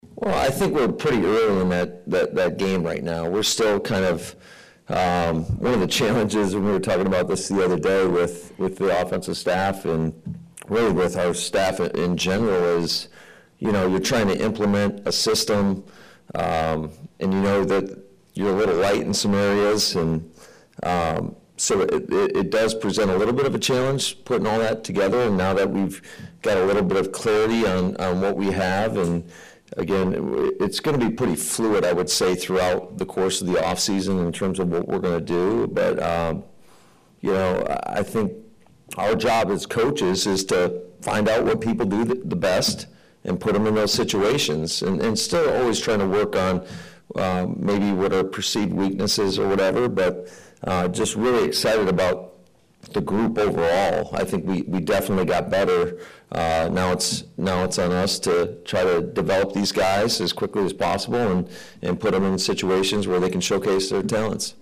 As for Head Coach Matt LaFleur, he made his only appearance of the weekend after the seventh round wrapped up with his biggest class yet and said now it’s time to coach ’em up.